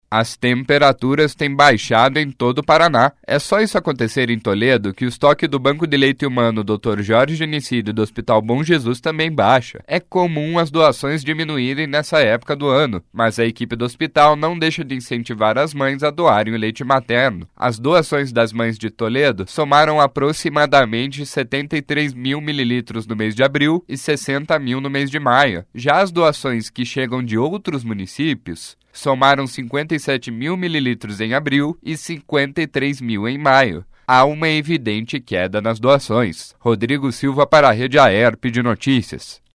06.06 – BOLETIM SEM TRILHA – Por conta do frio, doações de lei materno em Toledo estão em queda